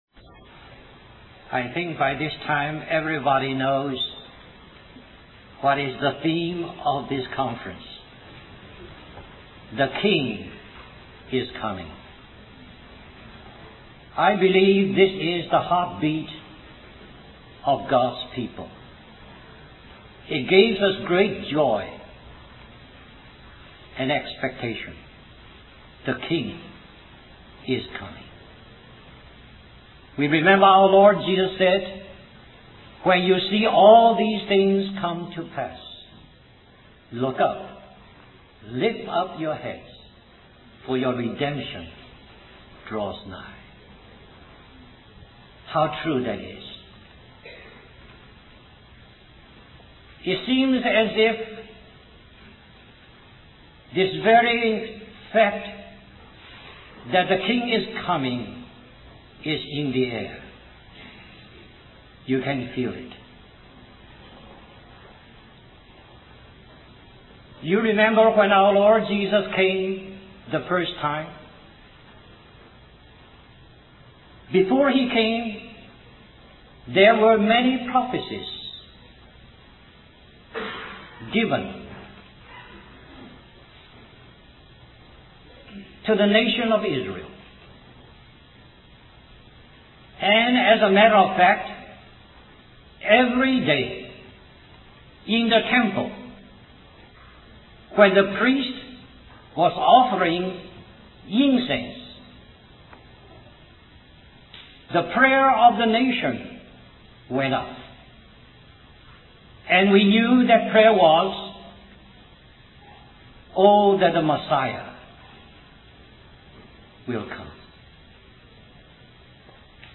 A collection of Christ focused messages published by the Christian Testimony Ministry in Richmond, VA.
1989 Christian Family Conference Stream or download mp3 Summary This message is continued in this message .